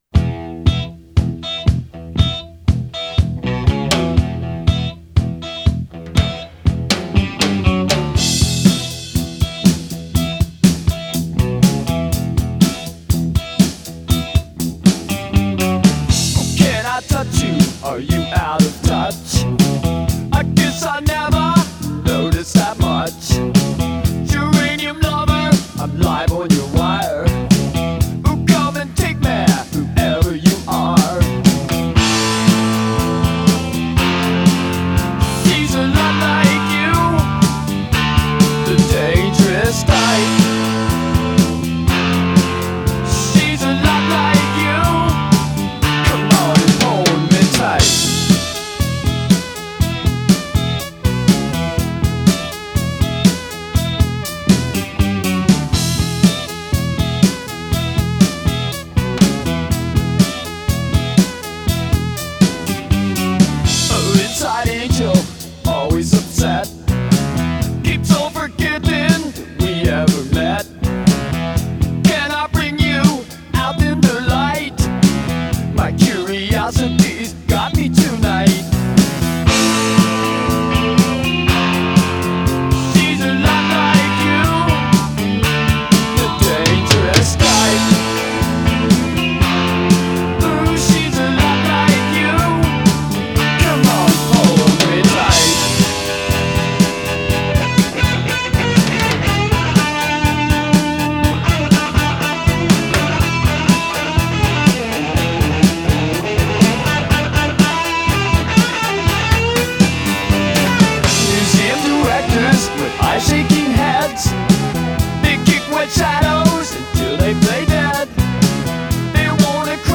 rhythm guitarist
keyboardist